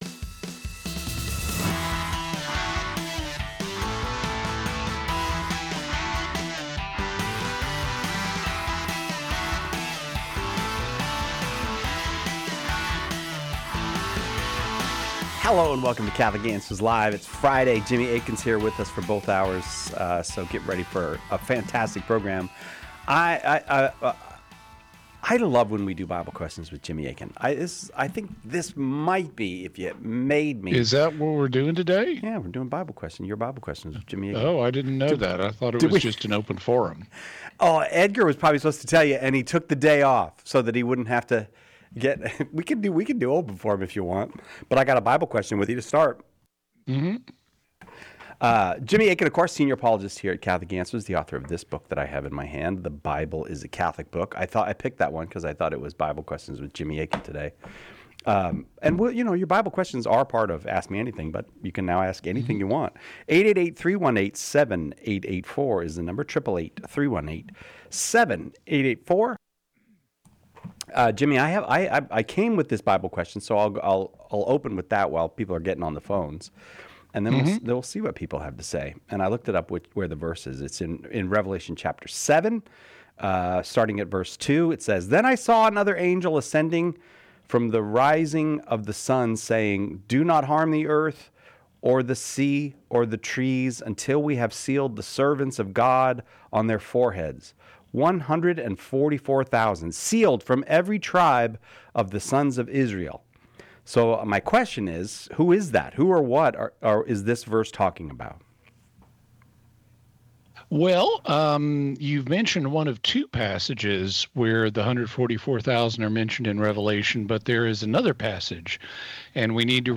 Join us for a thought-provoking discussion on these diverse theological inquiries.